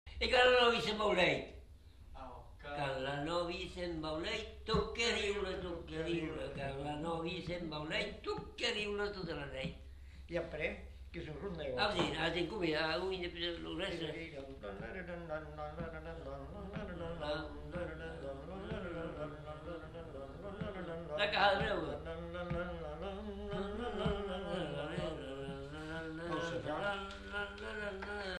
Lieu : Vielle-Soubiran
Genre : chant
Effectif : 1
Type de voix : voix d'homme
Production du son : chanté ; fredonné
Danse : rondeau